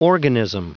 Prononciation du mot organism en anglais (fichier audio)
Prononciation du mot : organism
organism.wav